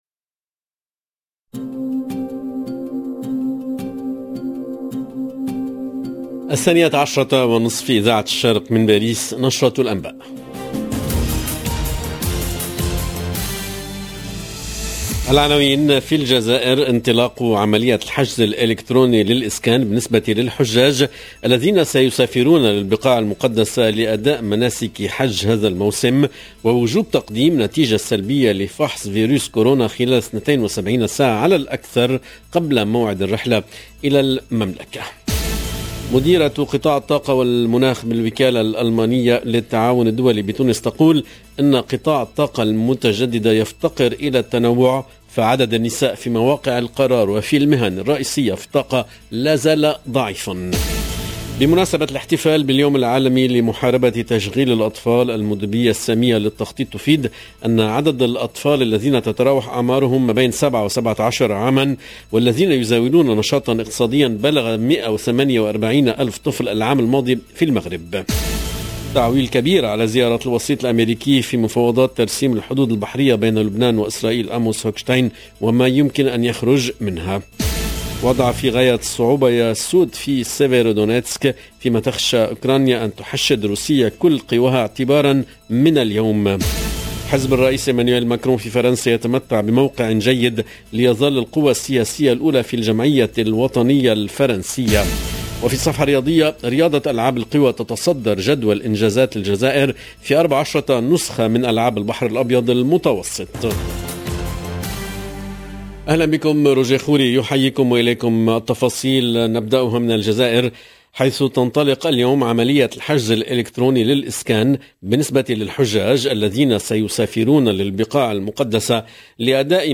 LE JOURNAL EN LANGUE ARABE DE MIDI 30 DU 13/06/22